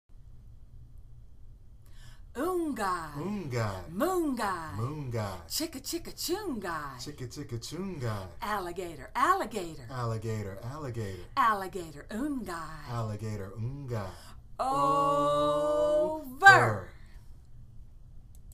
(all together) Vary the voices you use, for example, baby voice, cheerleader voice, or monster voice.